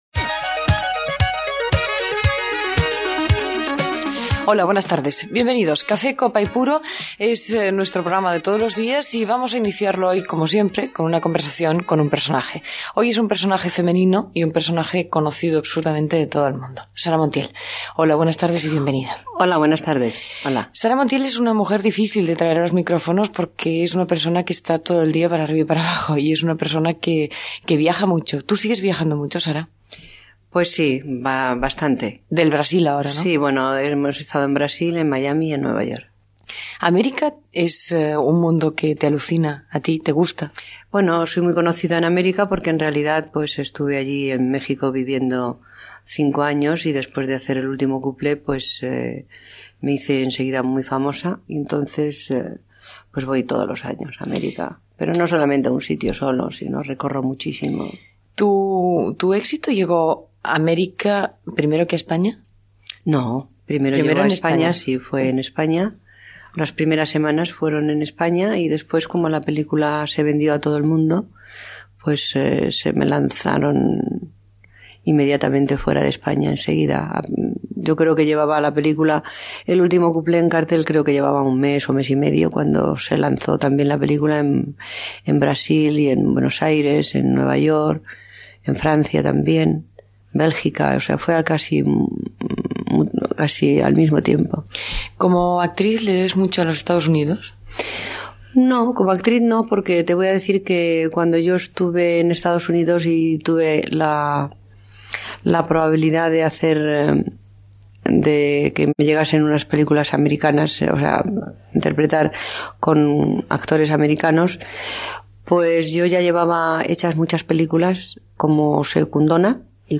Presentació i entrevista a l'actriu Sara Montiel qui parla de la seva feina a l'Amèrica llatina, del seu marit i de la tècnica cinemaogràfica
Entreteniment